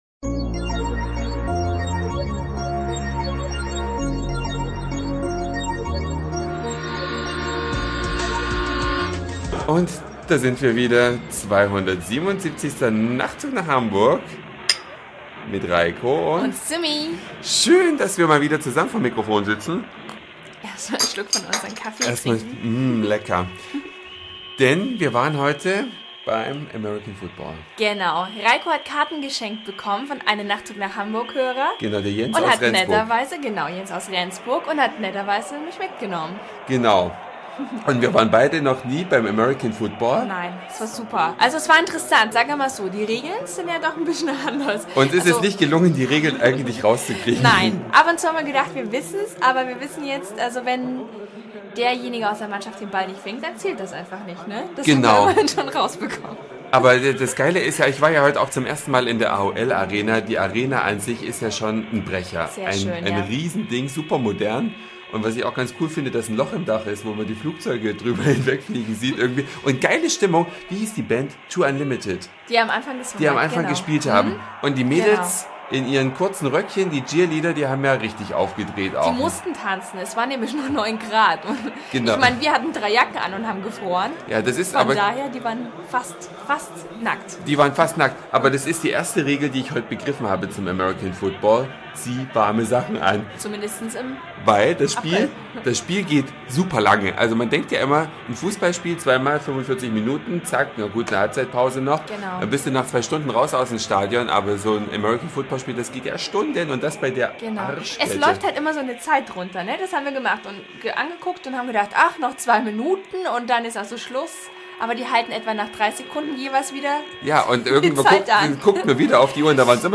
Eine Reise durch die Vielfalt aus Satire, Informationen, Soundseeing und Audioblog.
Spiel in der AOL-Arena in Hamburg. Coole Atmosphäre, Party und